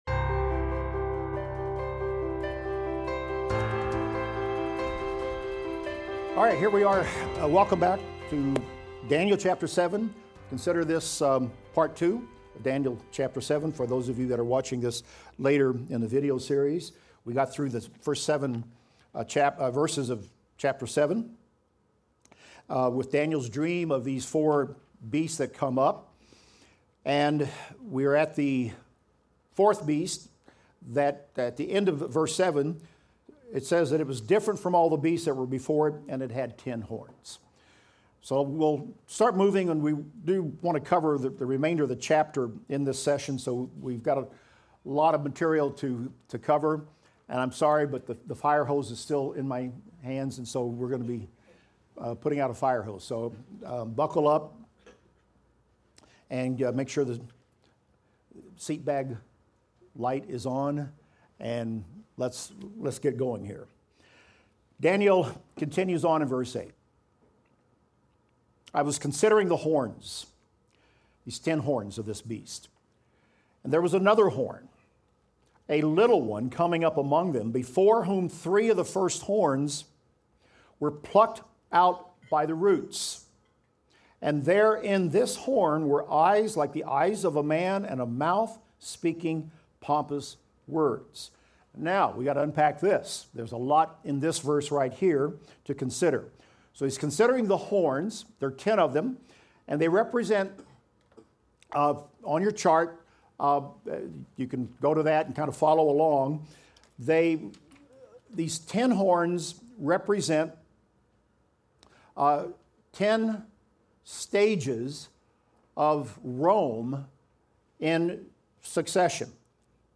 Daniel - Lecture 10 - audio.mp3